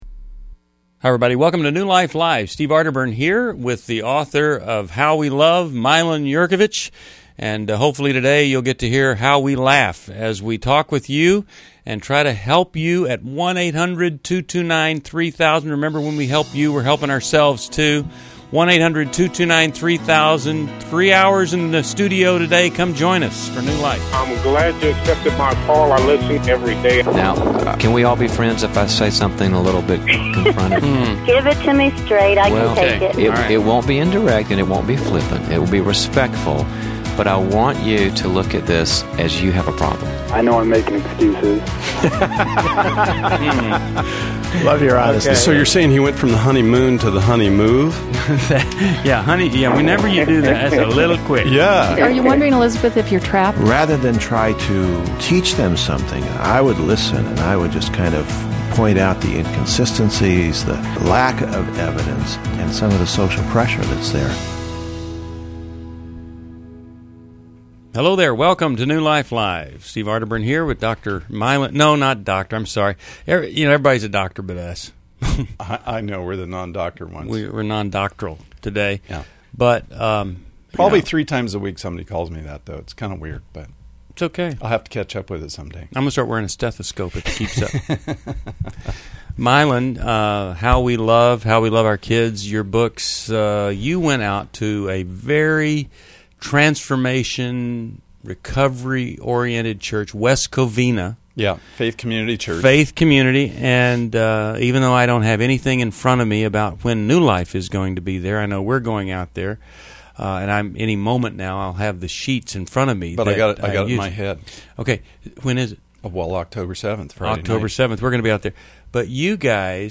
Explore relationship challenges on New Life Live: September 14, 2011, as hosts tackle divorce, addiction, codependency, and healing from abuse.
Caller Questions: 1. Should I make one last attempt to stop my divorce or let it go? 2.